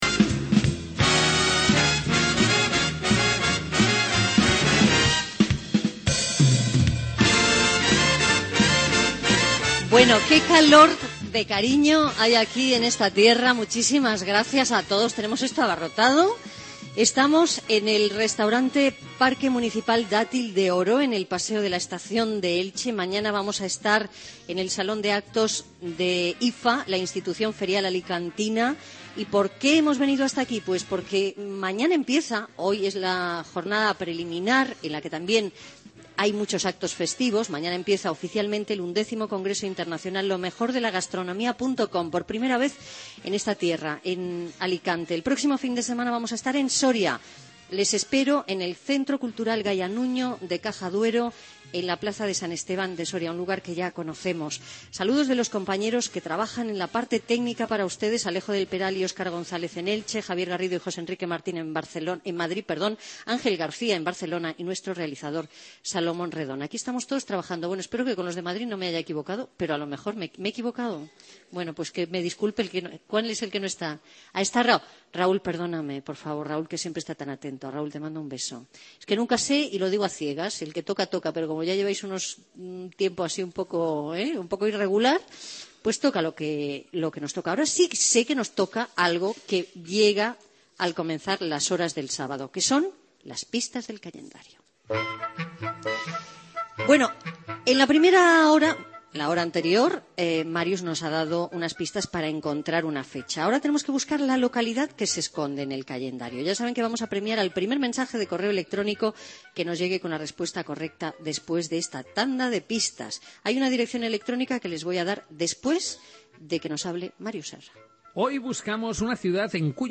Fragment d'un programa especial fet des d'Elx (Alacant).
Entreteniment